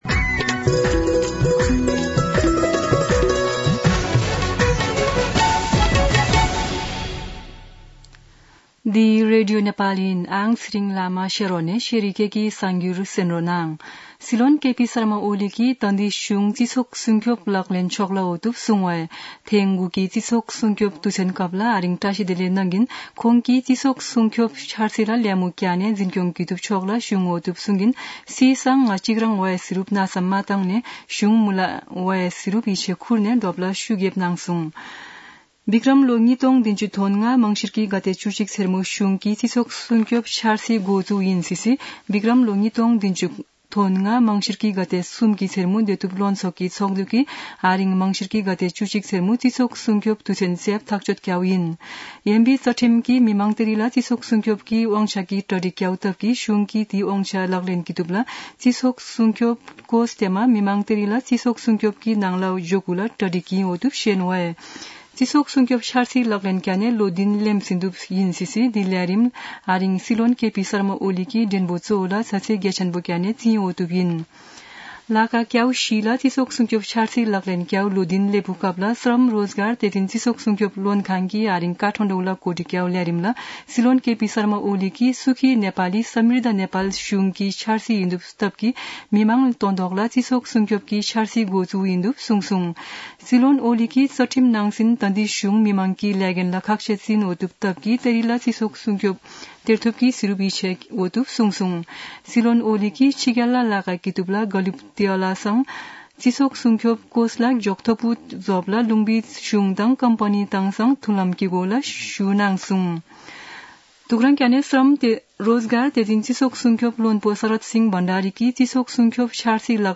शेर्पा भाषाको समाचार : १२ मंसिर , २०८१
4-pm-Sherpa-news-1-4.mp3